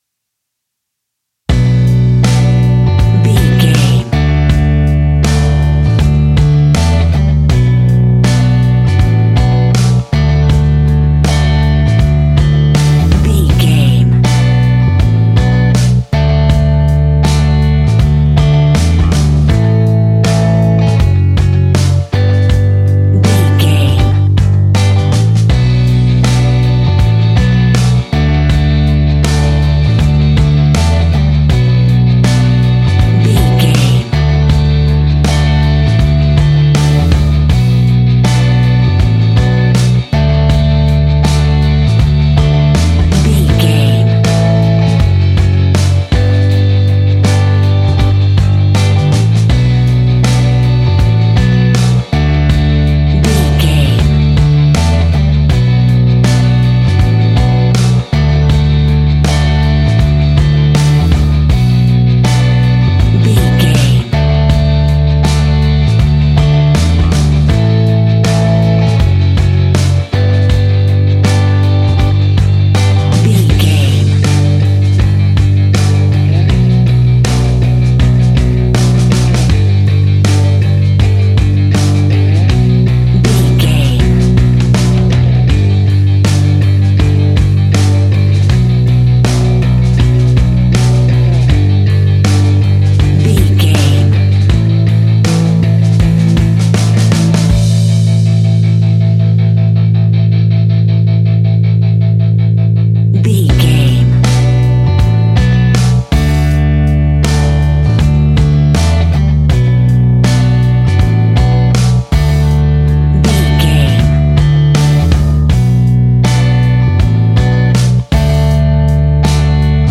Aeolian/Minor
guitars
hard rock
blues rock
distortion
instrumentals
Rock Bass
Rock Drums
heavy drums
distorted guitars
hammond organ